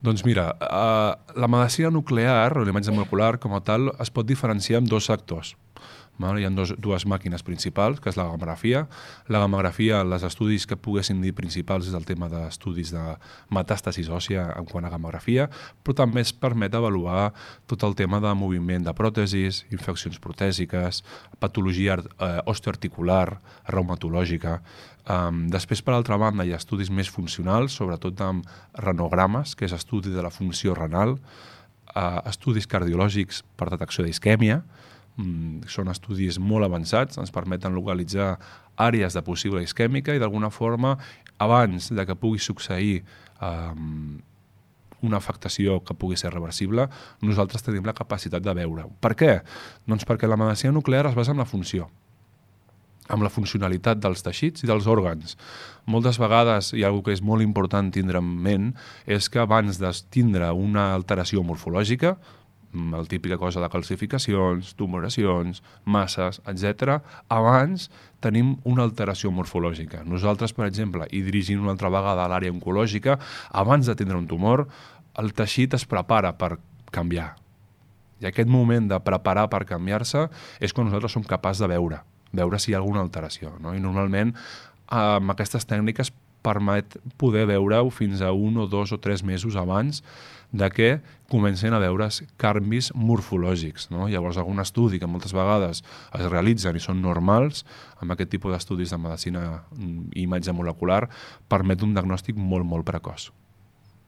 En una entrevista a Ràdio Capital